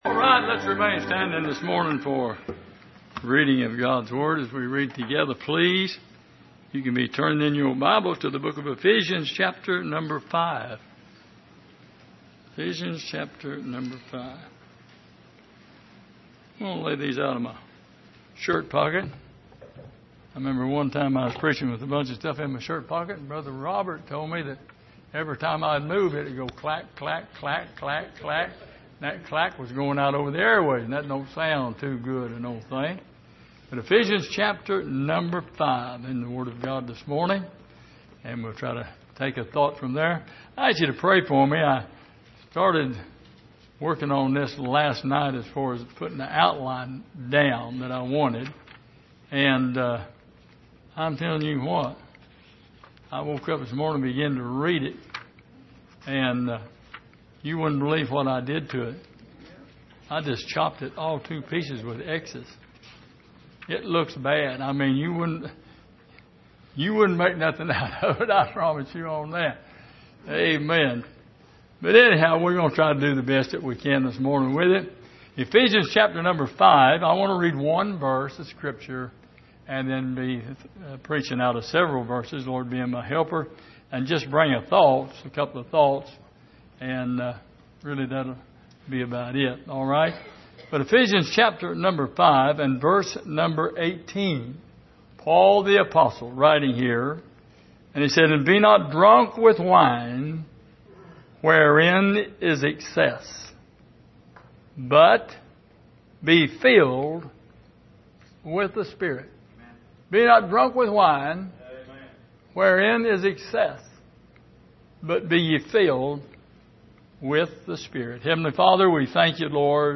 Passage: Ephesians 5:18 Service: Sunday Morning